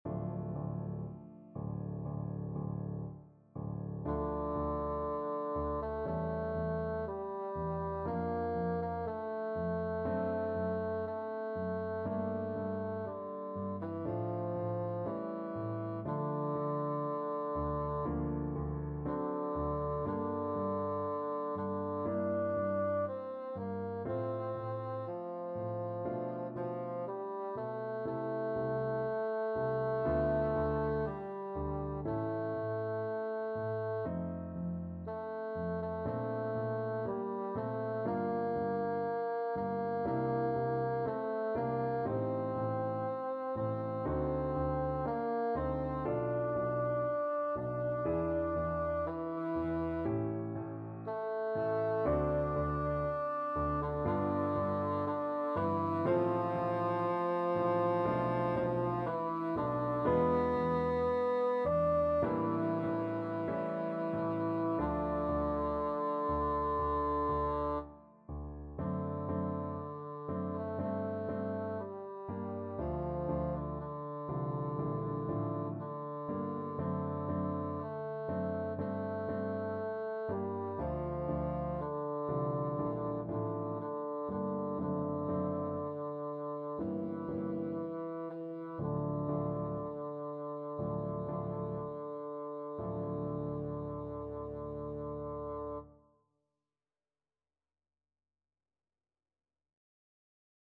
Bassoon
Molto Moderato = 60
2/2 (View more 2/2 Music)
D minor (Sounding Pitch) (View more D minor Music for Bassoon )
Classical (View more Classical Bassoon Music)